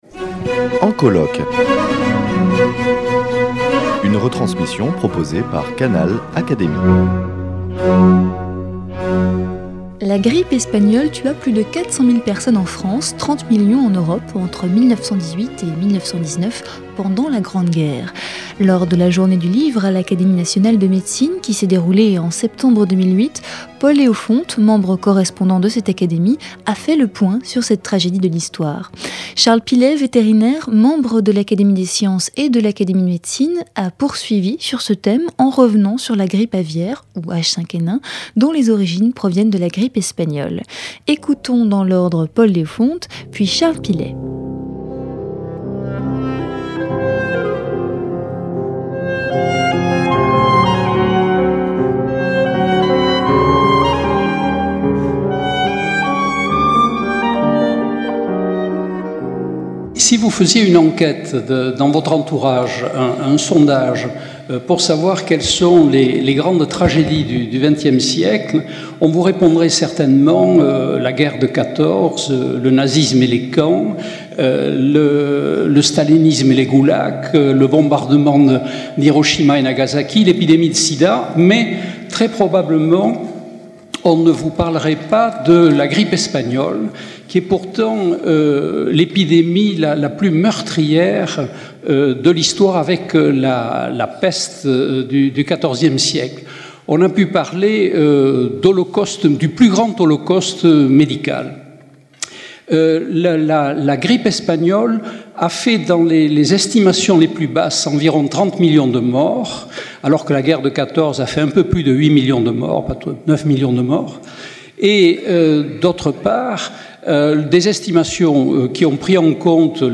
dans une communication commune qui se déroulait lors de la journée du livre à l’Académie nationale de médecine en septembre 2008